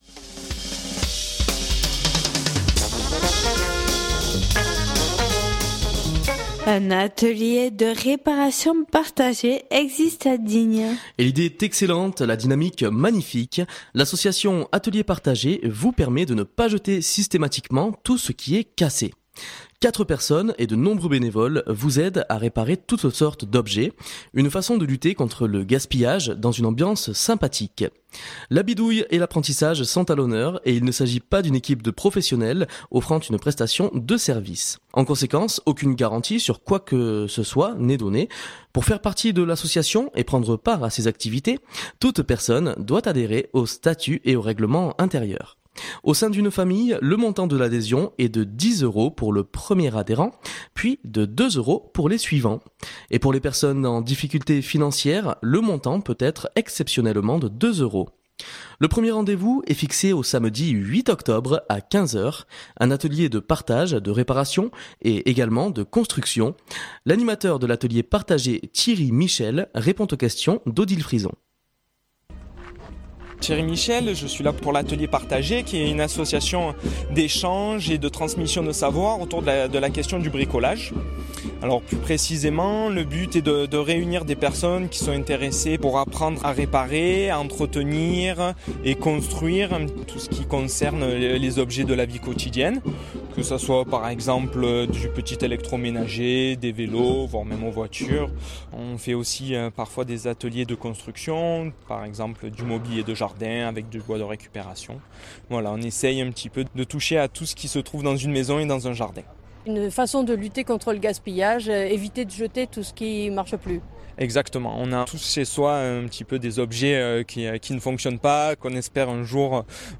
Atelier partagé de réparation lors du Forum des associations à Digne-les-Bains L’idée est excellente, la dynamique magnifique : l’association Atelier Partagé vous permet de ne pas jeter systématiquement tout ce qui est cassé. Quatre personnes et de nombreux bénévoles vous aident à réparer toutes sortes d’objets.